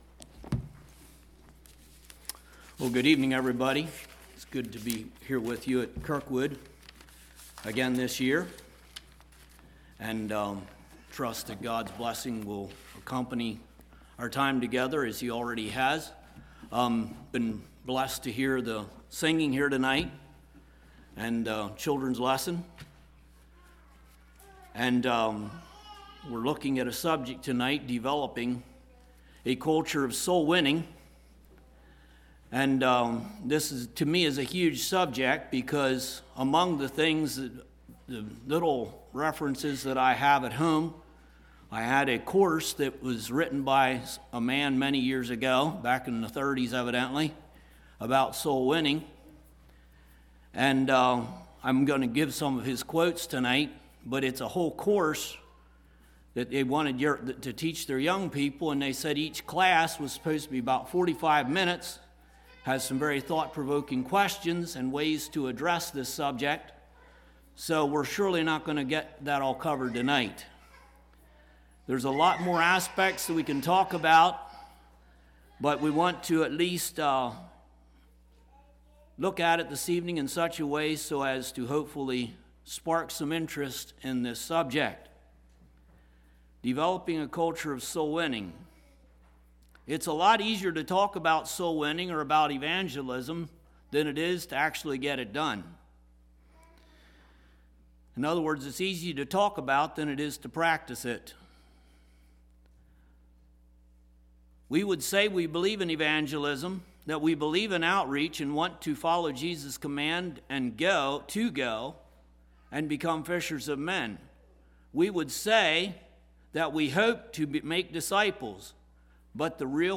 A message on soul-winning (bringing people to Christ, and Biblical obedience).